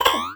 cartoon_boing_retro_jump_01.wav